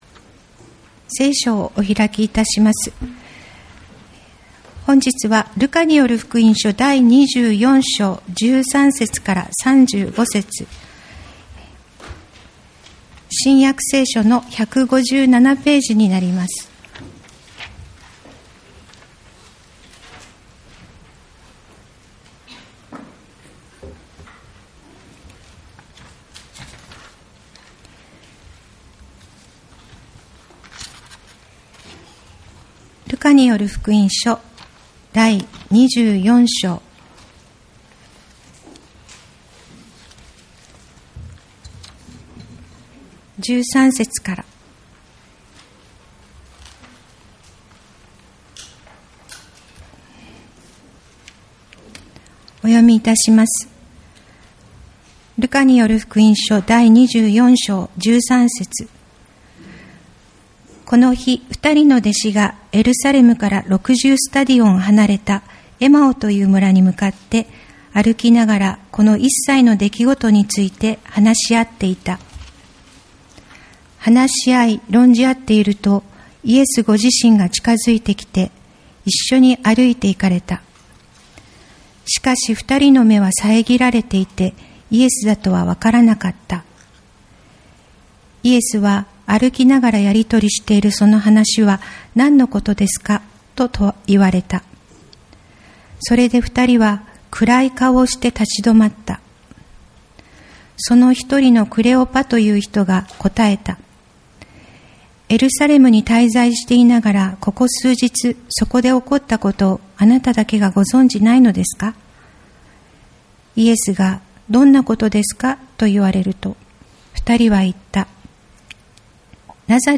日本ホーリネス教団 八王子キリスト教会 礼拝説教